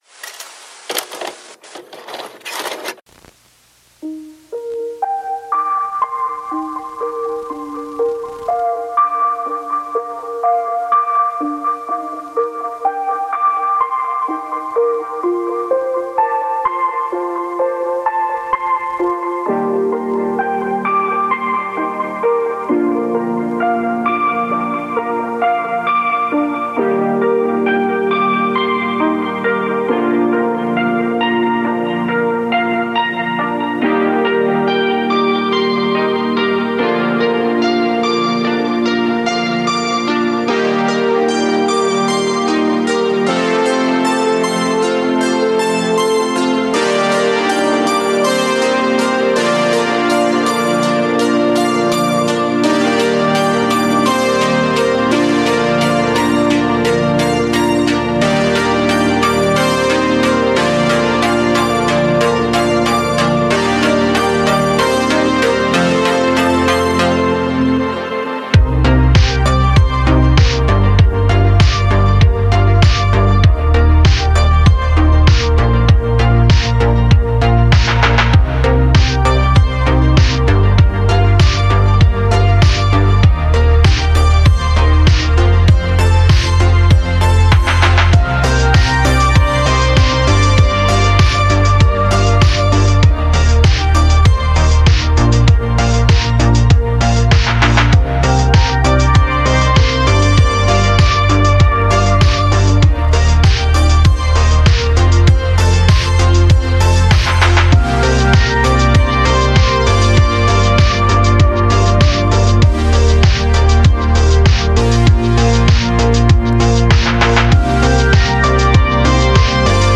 synthwave
The impressive retro track